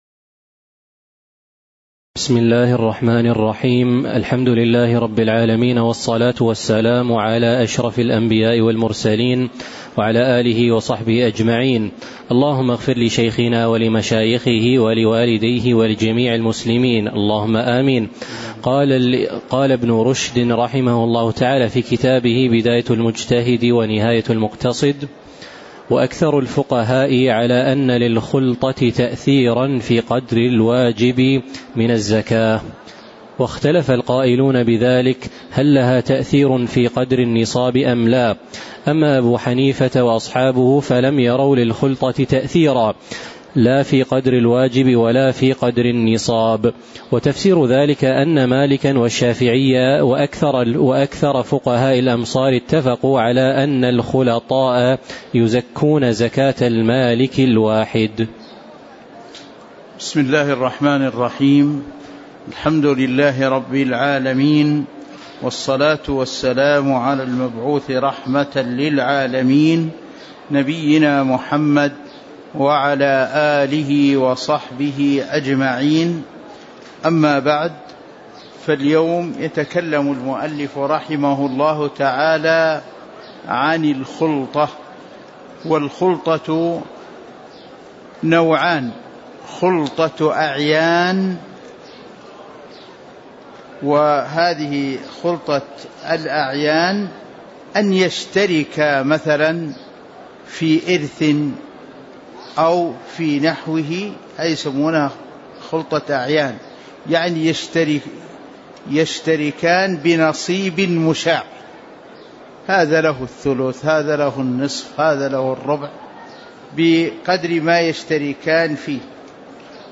تاريخ النشر ١ شعبان ١٤٤٥ هـ المكان: المسجد النبوي الشيخ